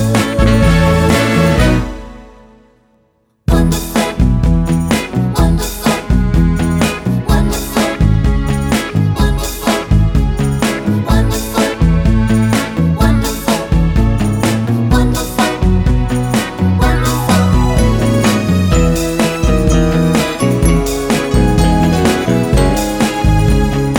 no Backing Vocals Jazz / Swing 3:04 Buy £1.50